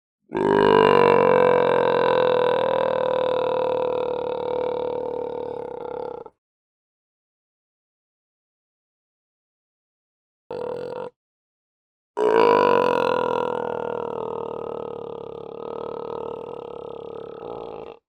На этой странице собраны разнообразные звуки морских львов – от их громкого рычания до игривого плеска в воде.
Звуки морского льва: какие звуки издает морской лев